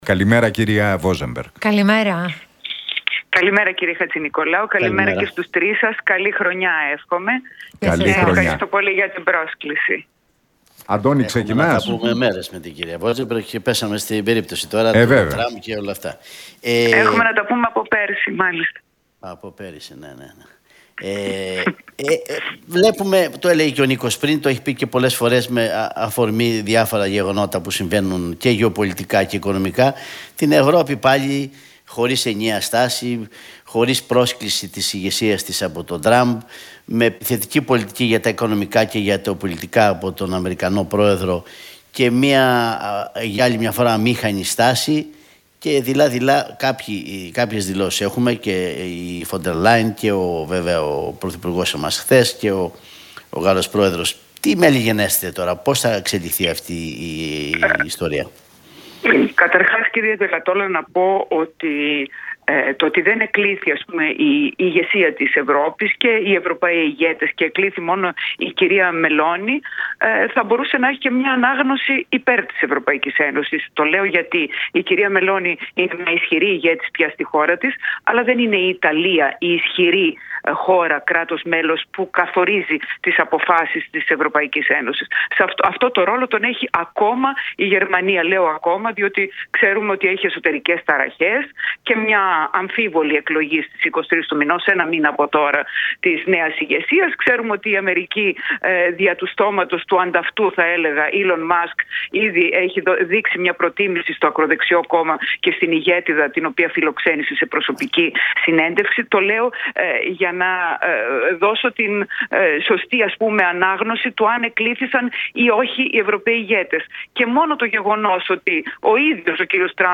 Βόζεμπεργκ στον Realfm: Εάν ο Τραμπ κηρύξει εκτεταμένα τον εμπορικό πόλεμο με την επιβολή δασμών, τα αντίποινα της ΕΕ δεν θα τον αφήσουν αδιάφορο